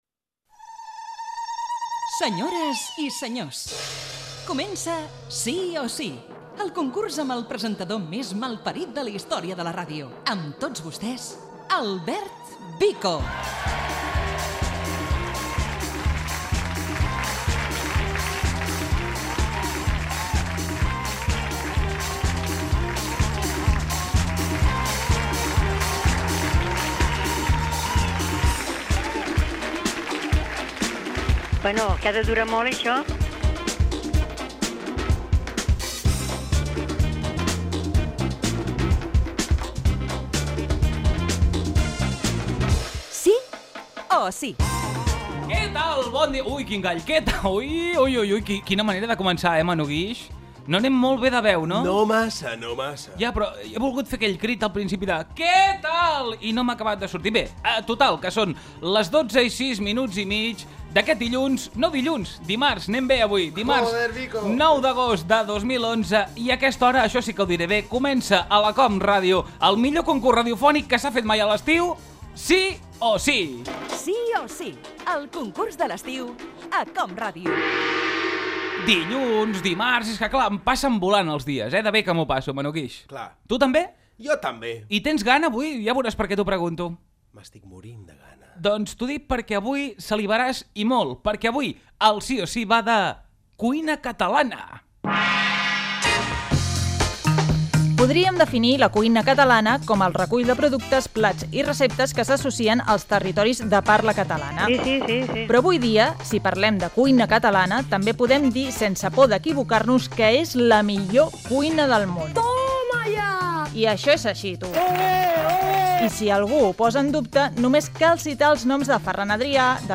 Careta del programa, inici del concurs d'estiu.
Entreteniment